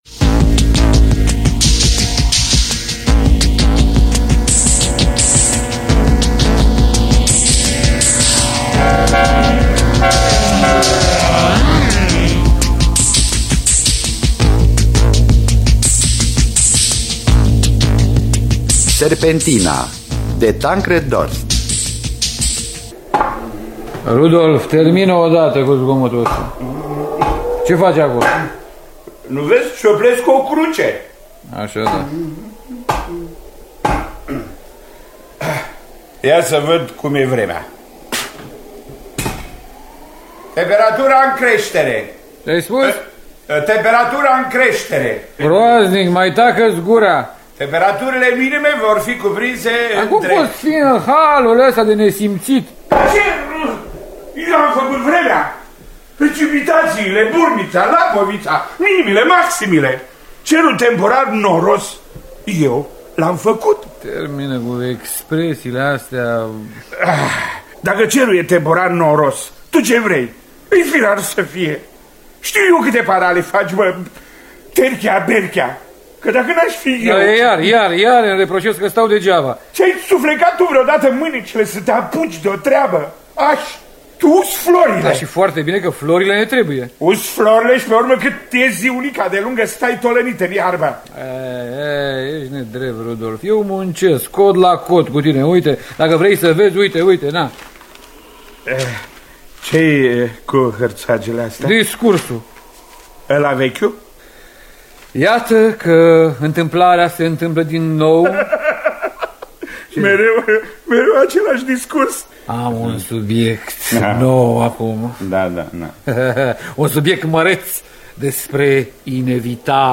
În distribuţie: Horaţiu Mălăele, Alexandru Arşinel, Mircea Albulescu.